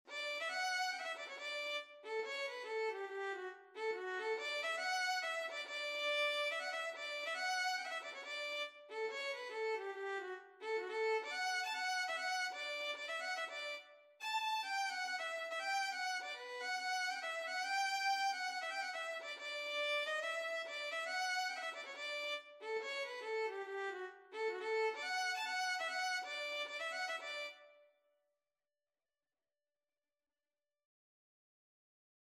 D major (Sounding Pitch) (View more D major Music for Violin )
4/4 (View more 4/4 Music)
F#5-A6
Violin  (View more Intermediate Violin Music)
Traditional (View more Traditional Violin Music)